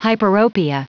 Prononciation du mot hyperopia en anglais (fichier audio)
Prononciation du mot : hyperopia